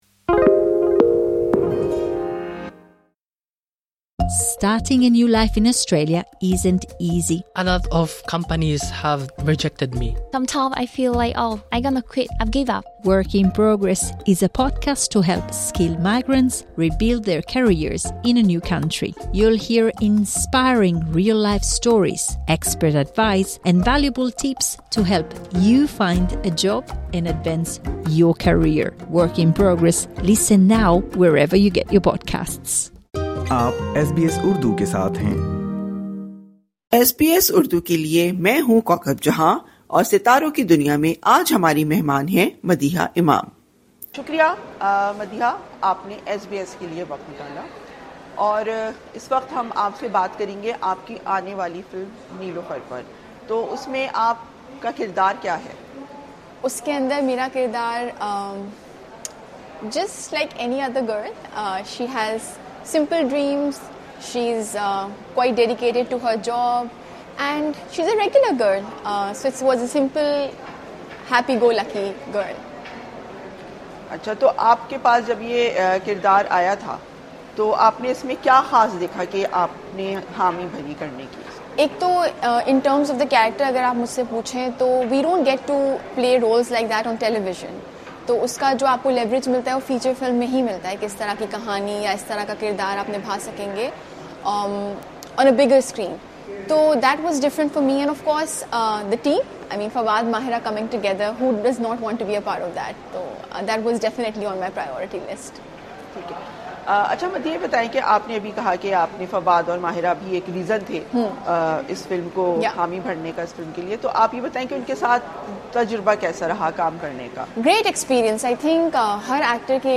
In an exclusive interview with SBS, Madiha Imam spoke about her role in Niloofar, her experience working in Bollywood, and topics related to Pakistani films.